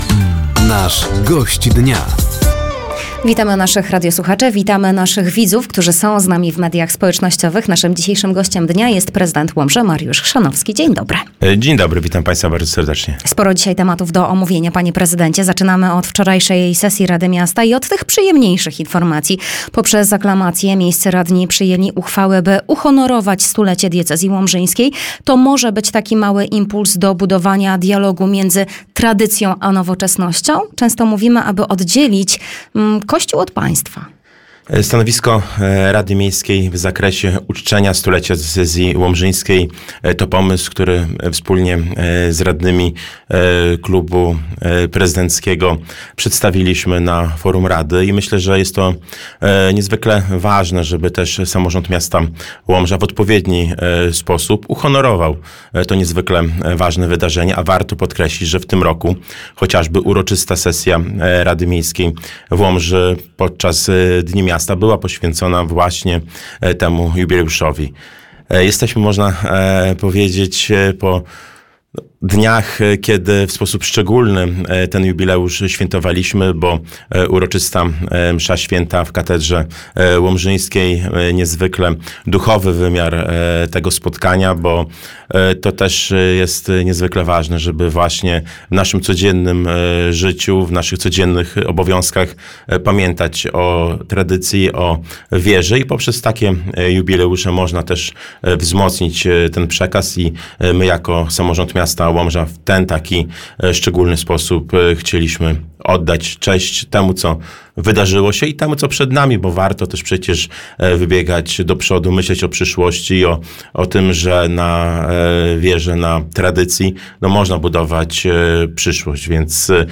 Gościem Dnia Radia Nadzieja był prezydent Łomży Mariusz Chrzanowski. Tematem rozmowy były ostatnie podwyżki podatku od nieruchomości, sytuacja finansowa samorządu, inwestycje oraz dwukadencyjność w samorządach.